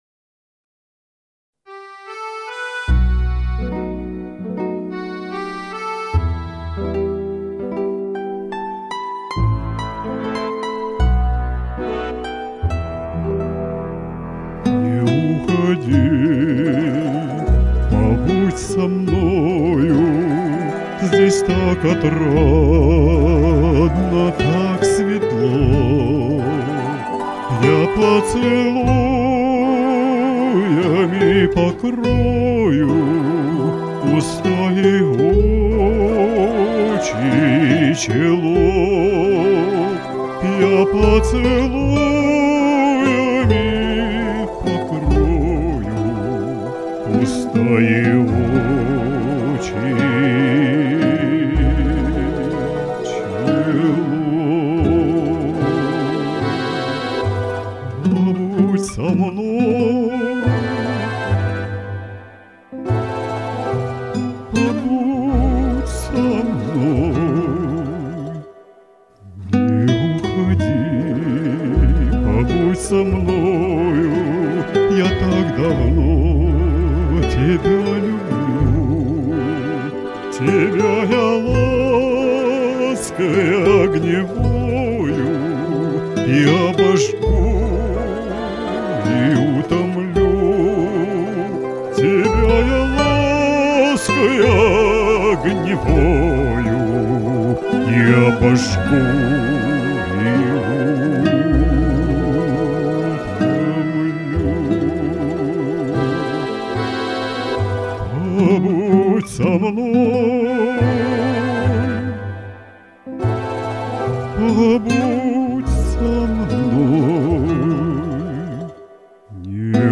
Разные голоса, но каждый спел отлично!
Вы так вкрадчиво начали, что я уж думала, а и правда - останусь!